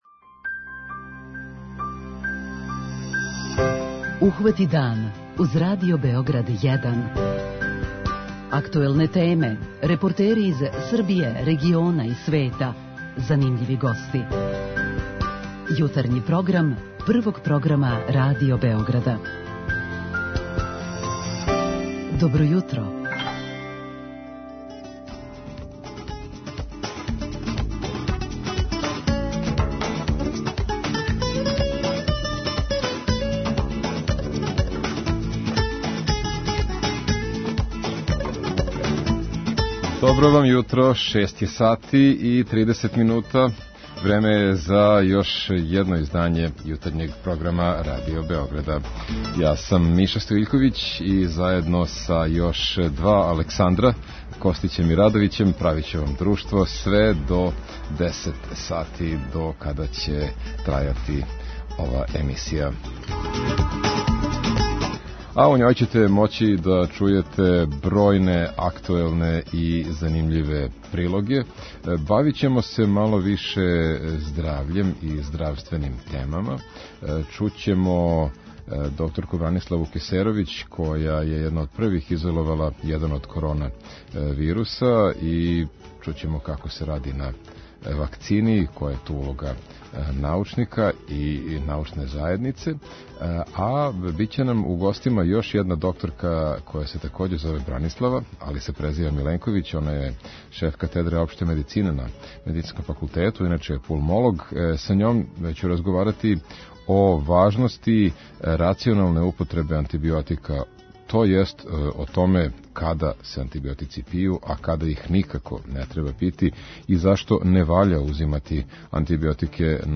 У редовној рубрици "Питање јутра" слушаоце ћемо питати када и како су стекли кров над главом и да ли је некад било лакше доћи до стана. Повод нам је истраживање које је показало да само 6% младих има свој стан.
преузми : 37.78 MB Ухвати дан Autor: Група аутора Јутарњи програм Радио Београда 1!